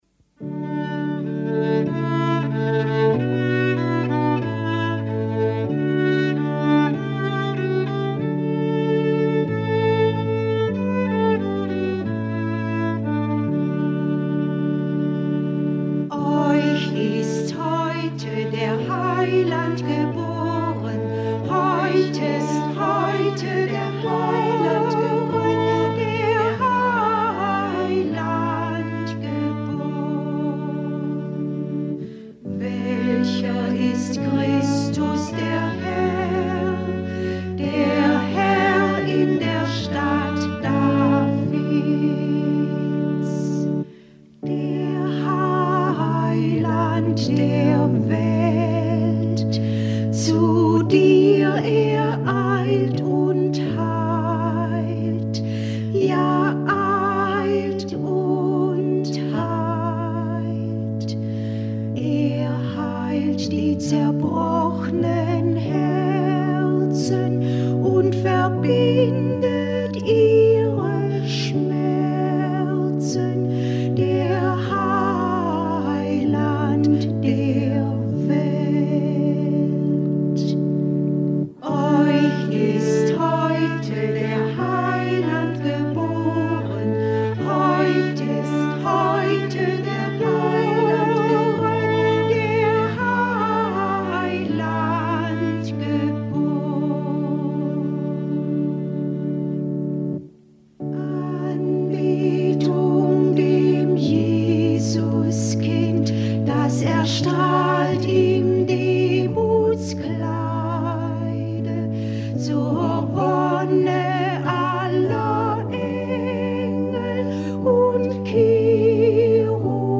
Chor_Euch_ist_heute_der_Heiland_geboren.mp3